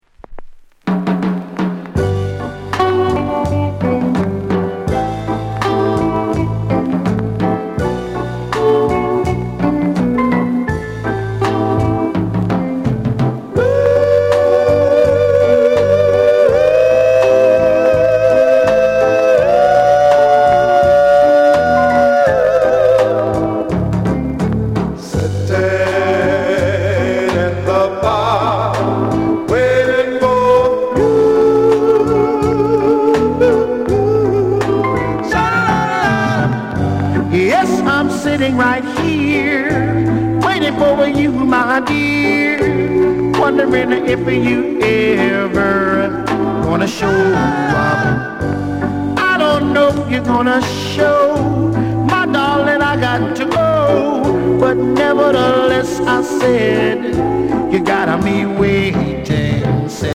SOUND CONDITION VG(OK)